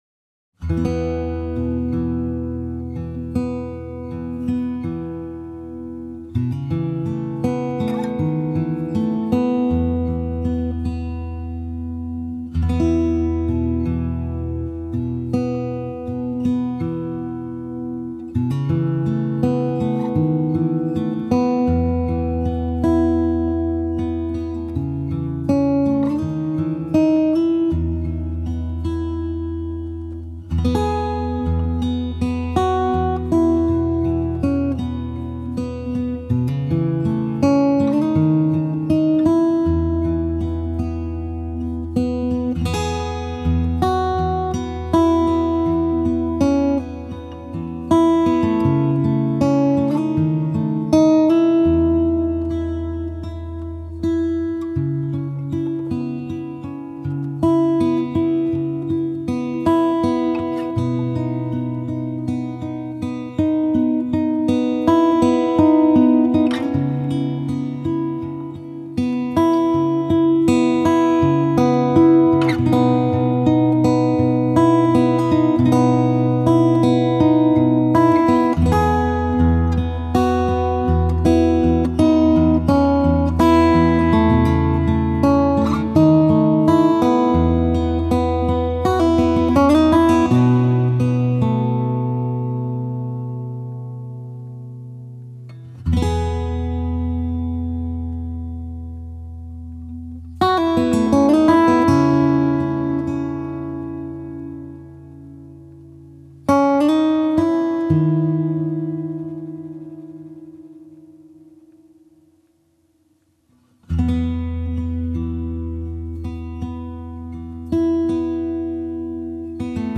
سبک آرامش بخش , ملل , موسیقی بی کلام
موسیقی بی کلام گیتار آکوستیک